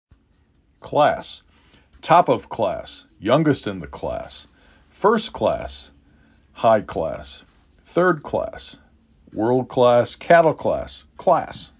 5 Letters, 1 Syllable
k l a s